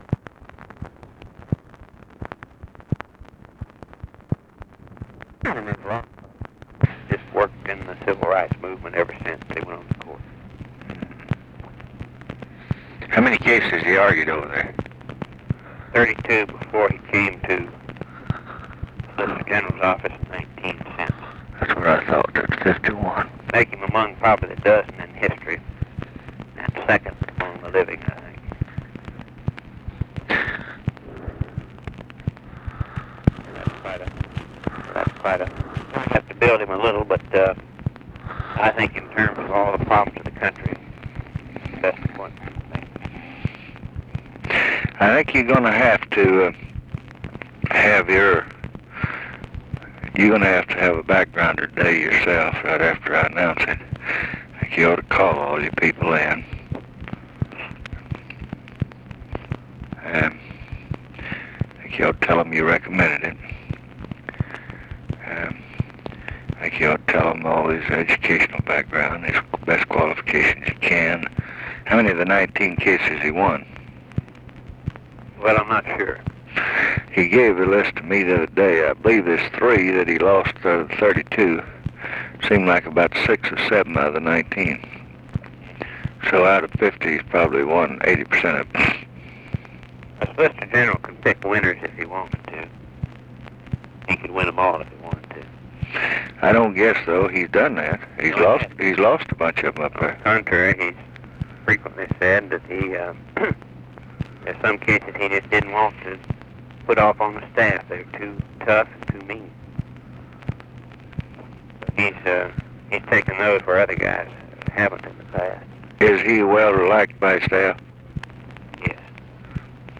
Conversation with RAMSEY CLARK and OFFICE CONVERSATION, June 13, 1967
Secret White House Tapes